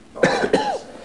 Cough Sound Effect
Download a high-quality cough sound effect.
cough-2.mp3